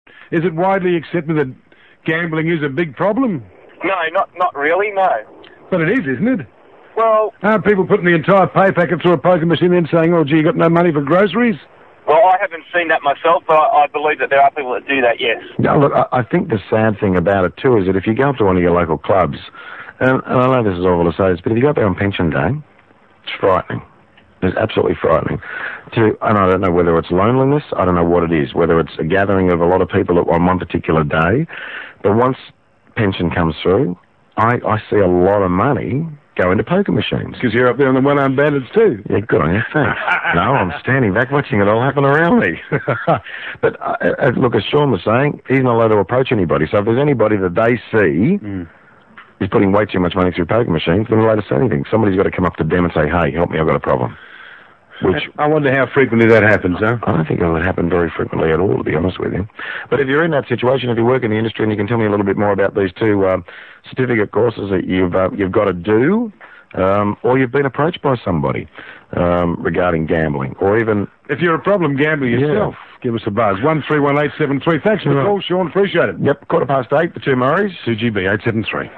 Australian English, broad
The features of broad Australian English are extensions and continuations of features and processes present in the more general form of English in this country. In particular the diphthong shift down and back is more pronounced, e.g. tray would be [træi] rather than [trɛi] (general Australian English). There are other features such as a tapped realisation of /t/ in intervocalic position, e.g. later [lɛiſə].
Australia_Broad.wav